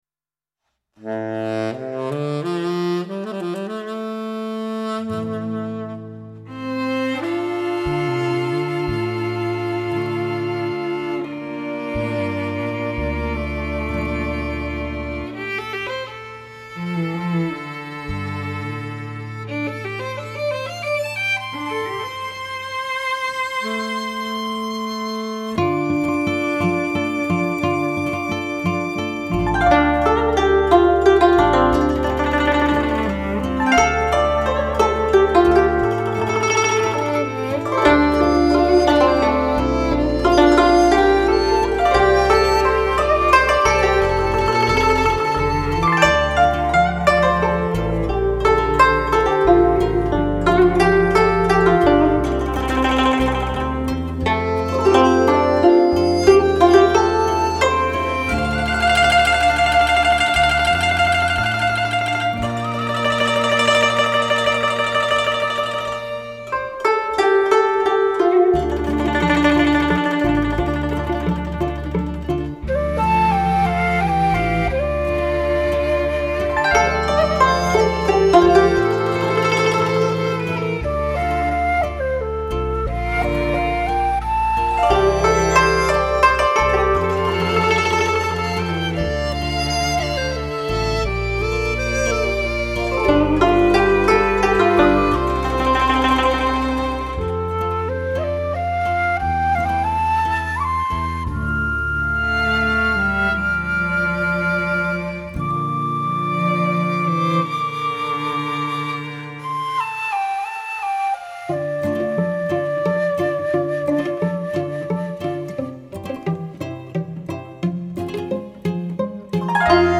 灵动的手指，婉约的筝音，
在丝丝的旋律中如风、如雨，
既有传统的神韵又有现代气息。